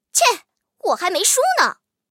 M3斯图亚特中破语音.OGG